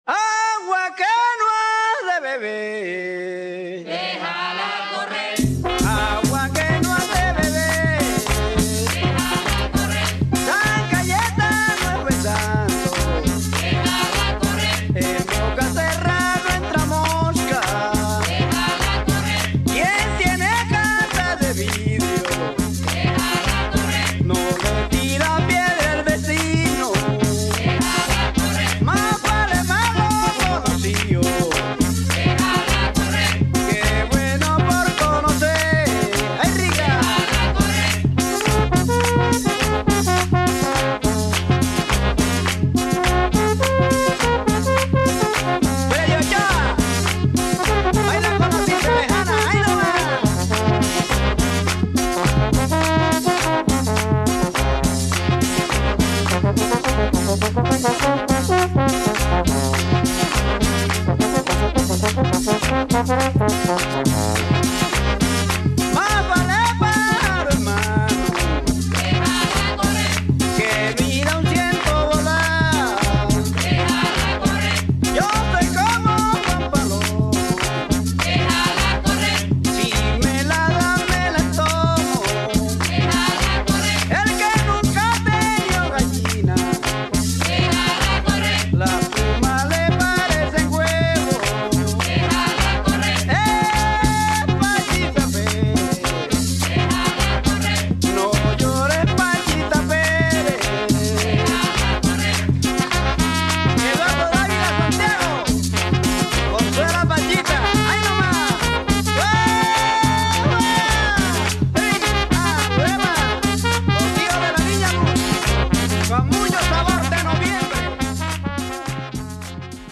cumbia